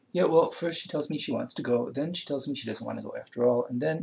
Examples with Bookended Narrow Pitch